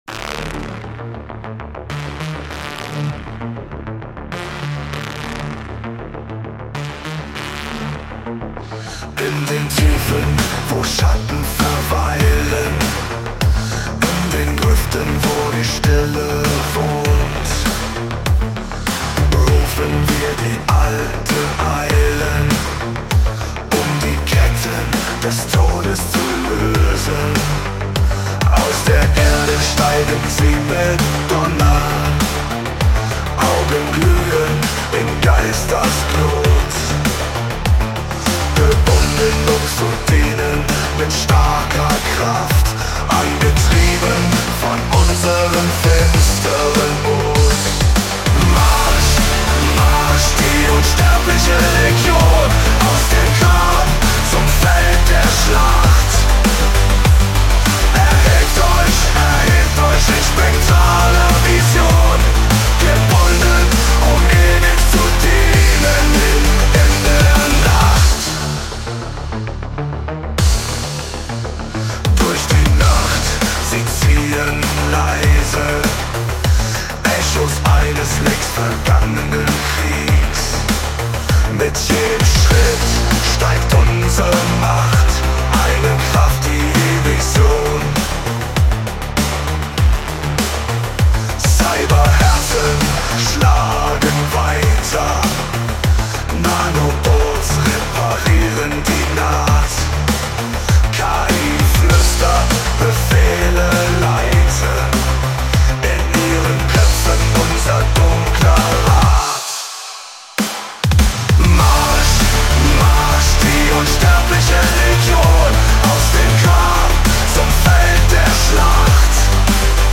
AI-generated music content